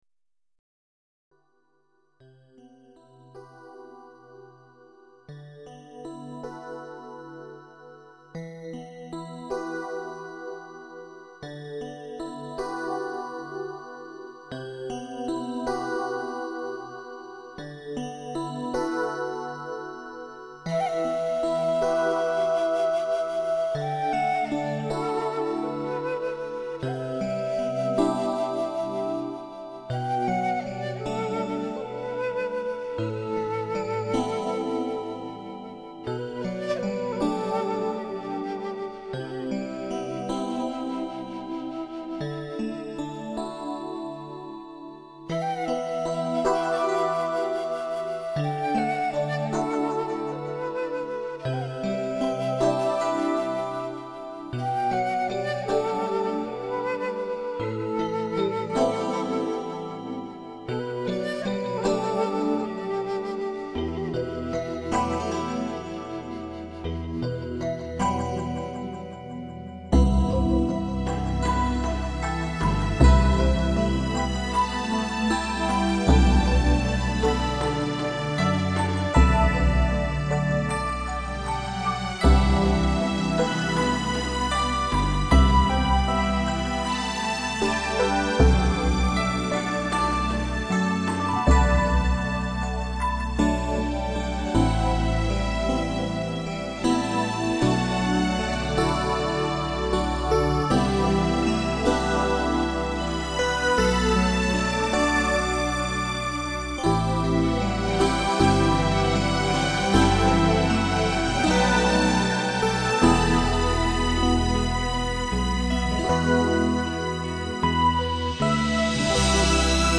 音乐极具中国古典风格的音乐
感伤的箫声 :grin: